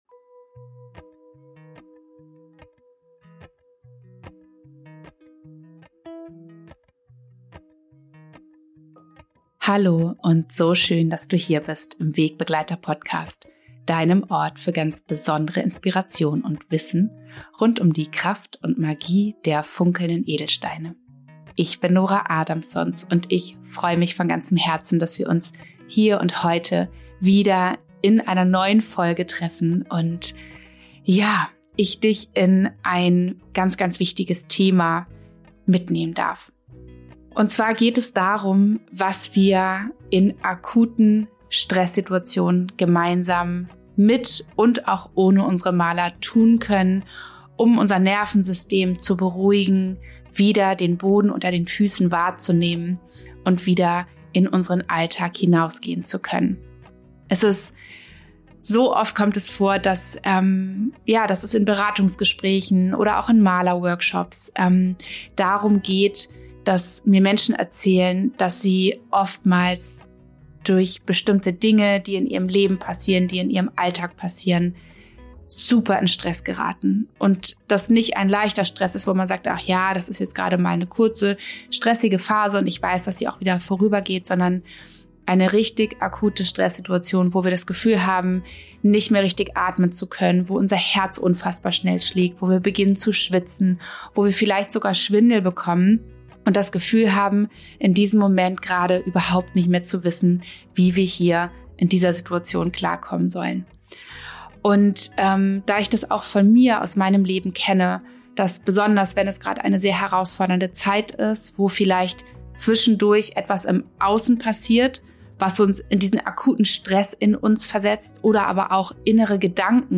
Seit einiger Zeit beschäftige ich mich intensiver damit, wie wir unser Nervensystem entspannen können und welche Übungen uns langfristig dabei helfen, in Stresssituationen Ruhe zu finden. Deswegen möchte ich dir heute neben Übungen, die dir kurzfristig helfen, dein Stressniveau zu senken, eine geführte Atemmeditation mit deiner Mala zeigen, die dir helfen kann, dein Nervensystem in akuten Stresssituationen zu beruhigen.